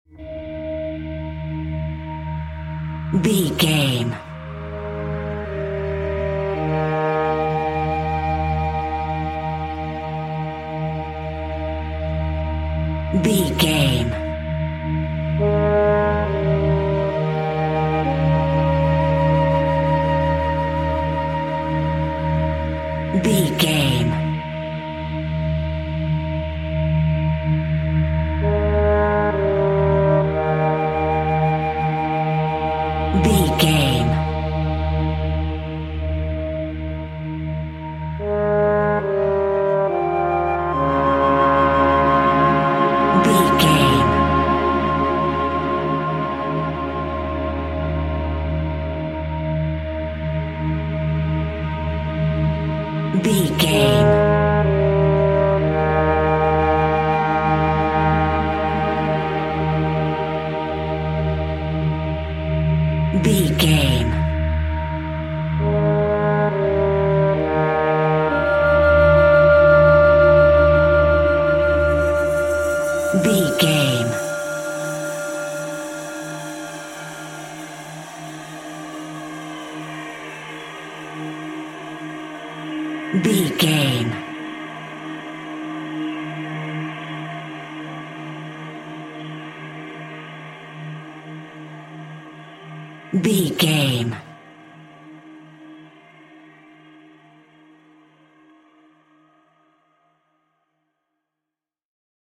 Ionian/Major
E♭
Slow
tension
ominous
eerie
orchestra
brass
flute
synthesizer
Horror synth
Horror Ambience